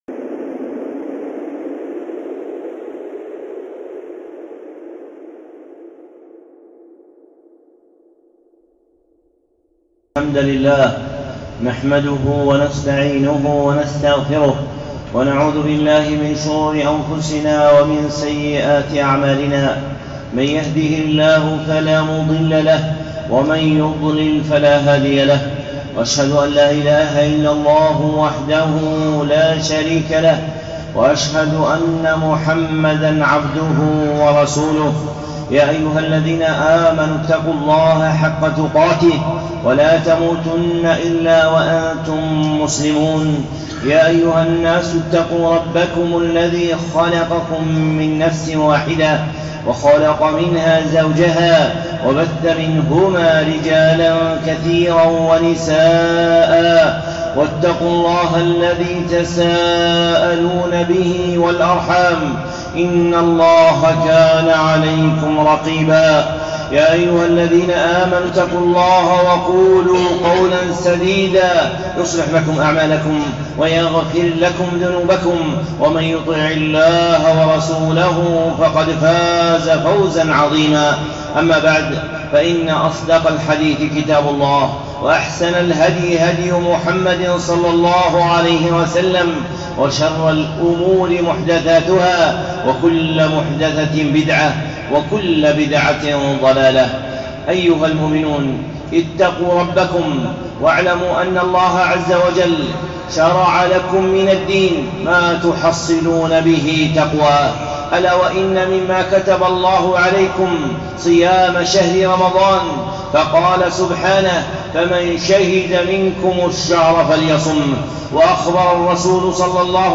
خطبة (الإمساك في رمضان)